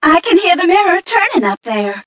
1 channel
mission_voice_m4ca020.mp3